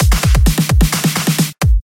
摇滚硬核鼓
描述：岩石中的硬核鼓
Tag: 130 bpm Rock Loops Drum Loops 319.86 KB wav Key : Unknown